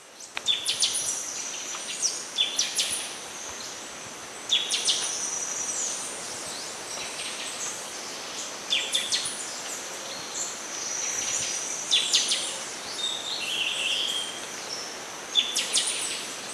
Sporophila frontalis (Verreaux, 1869)
Nome em Inglês: Buffy-fronted Seedeater
Local: RPPN Santuário Rã-bugio – Guaramirim – SC